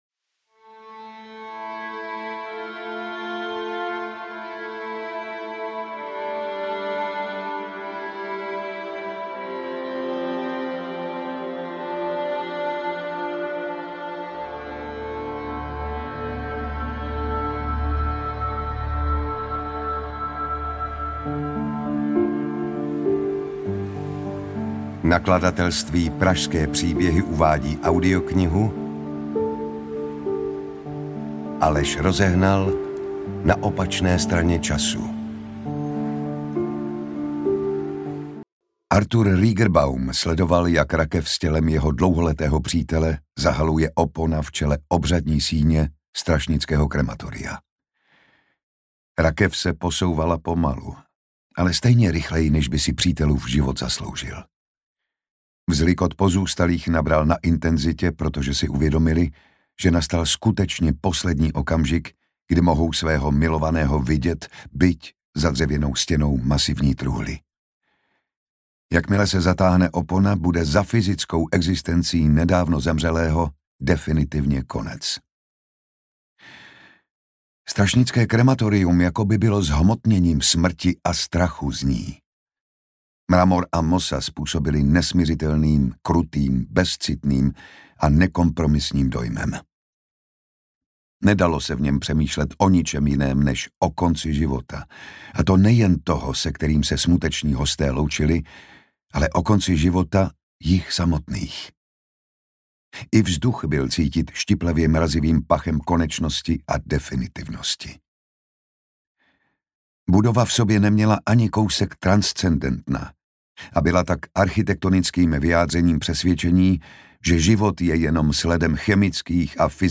Ukázka z knihy
• InterpretJan Štastný